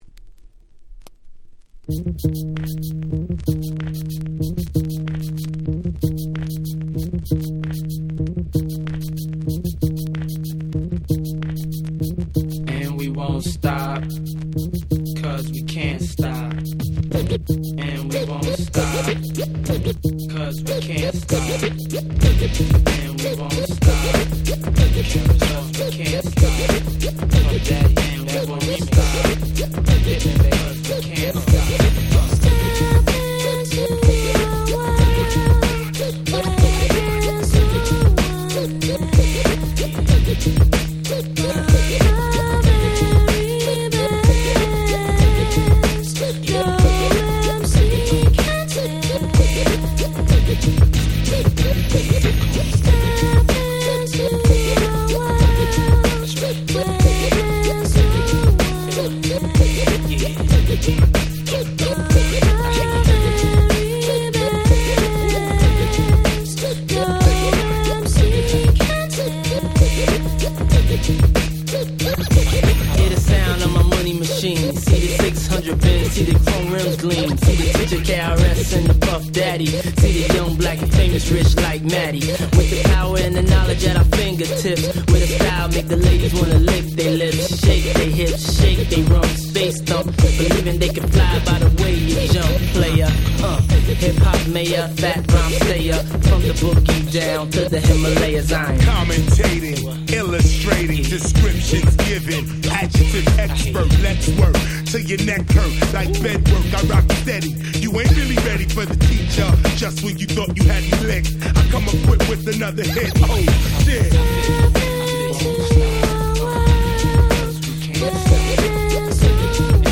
97' Smash Hit Hip Hop !!